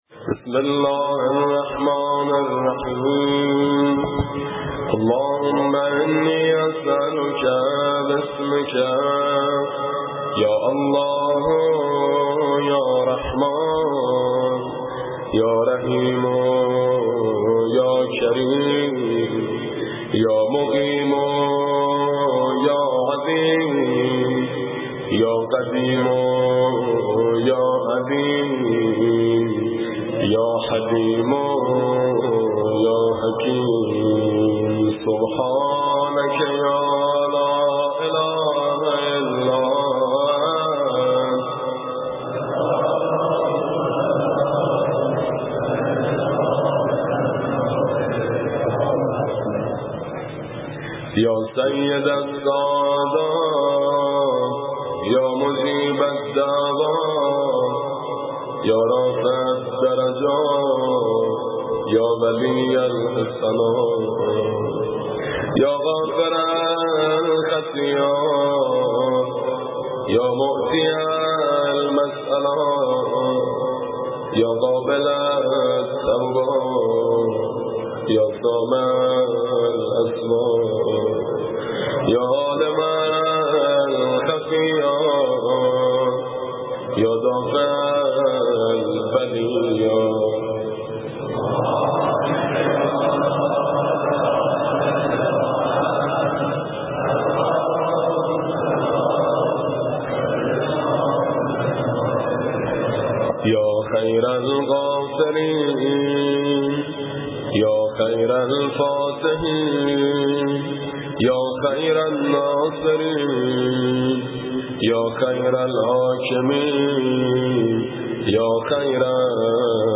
صوت/ دعای جوشن کبیر با صدای میثم مطیعی+ متن